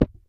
default_dig_oddly_breakable_by_hand.ogg